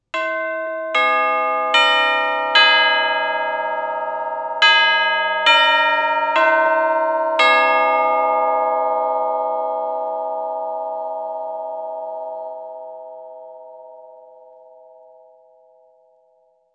2 channels
tubbells.wav